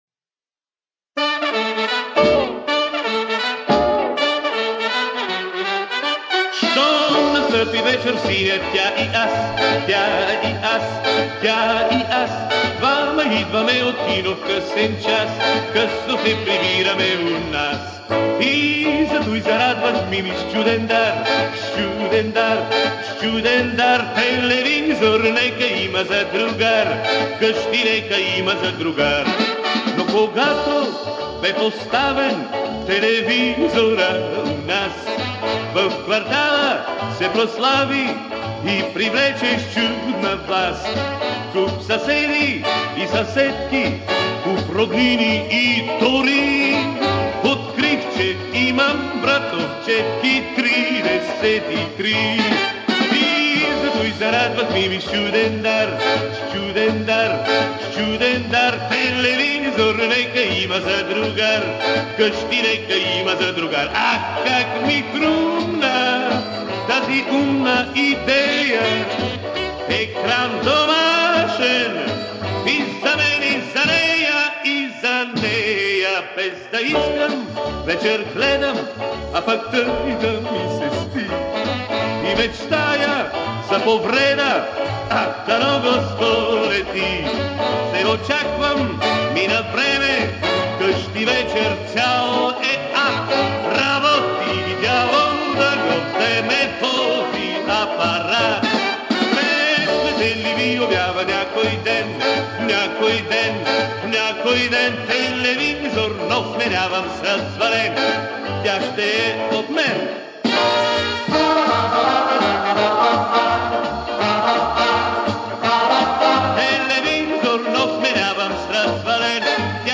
Записал 4 песни с пластиночки.